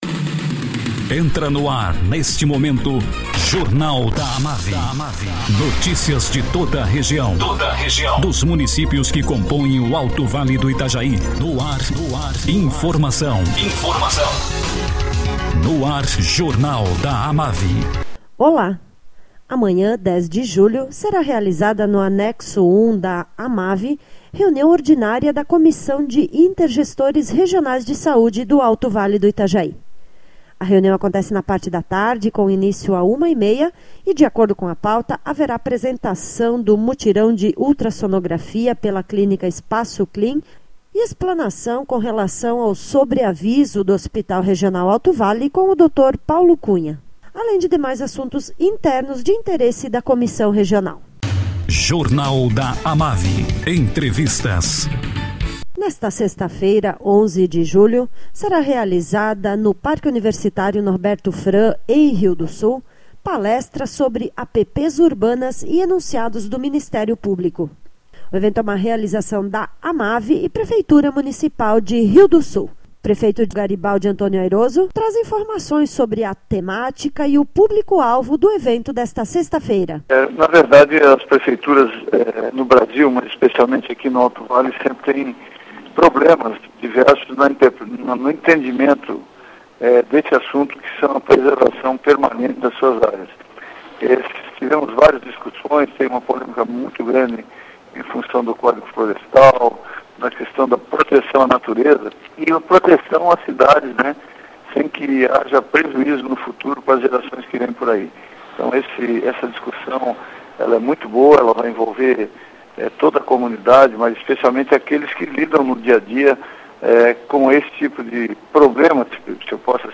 Prefeito de Rio do Sul, Garibaldi Antônio Ayroso, fala sobre palestra que será realizada nesta sexta-feira, sobre APPs Urbanas.